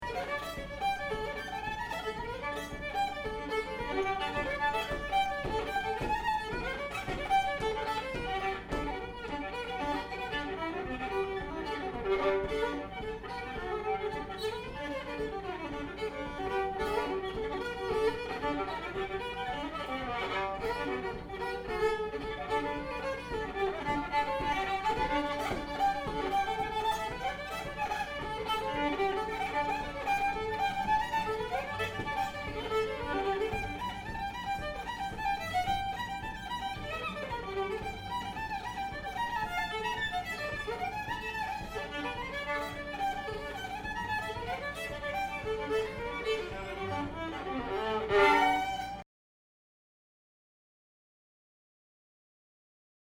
"McDonald's Reel," Manitoba version
Workshop, Toronto, May 2008.